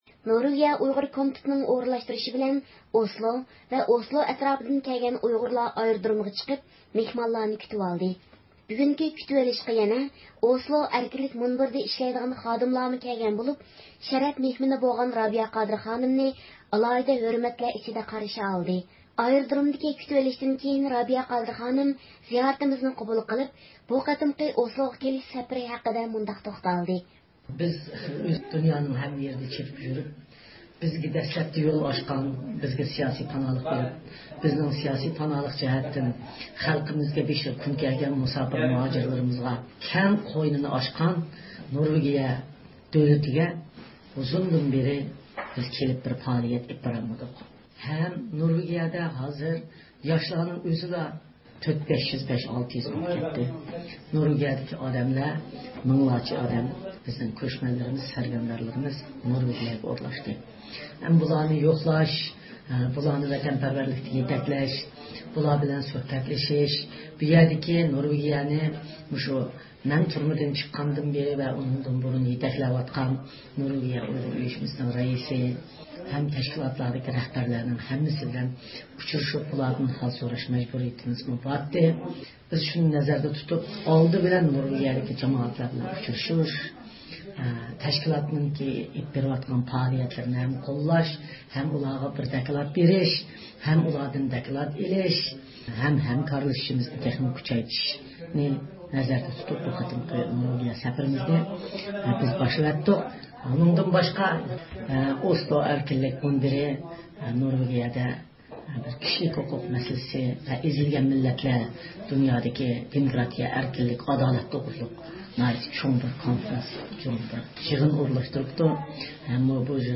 ئايرودرومدىكى كۈتۈۋېلىشتىن كېيىن، رابىيە قادىر خانىم زىيارىتىمىزنى قوبۇل قىلىپ، بۇ قېتىمقى ئوسلوغا كېلىش سەپىرى ھەمدە ئوسلو ئەركىنلىك مۇنبىرى ھەققىدە توختالدى.